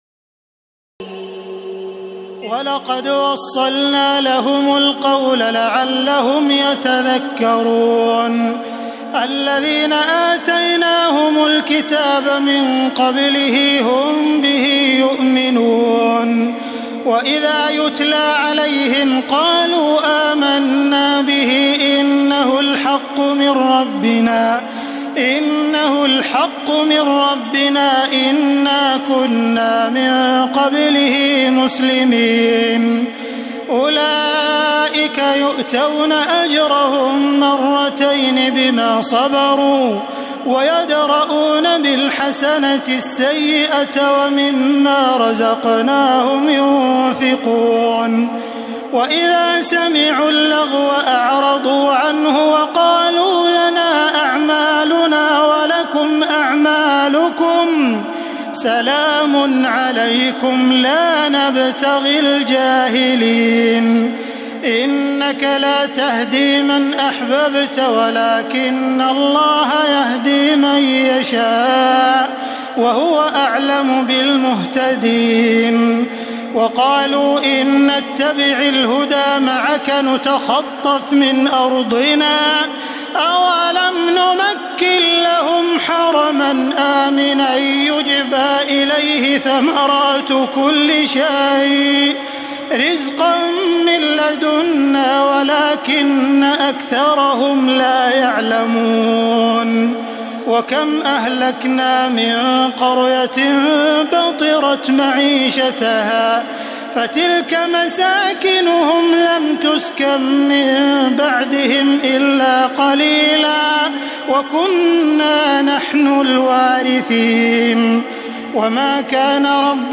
تراويح الليلة التاسعة عشر رمضان 1432هـ من سورتي القصص (51-88) والعنكبوت (1-45) Taraweeh 19 st night Ramadan 1432H from Surah Al-Qasas and Al-Ankaboot > تراويح الحرم المكي عام 1432 🕋 > التراويح - تلاوات الحرمين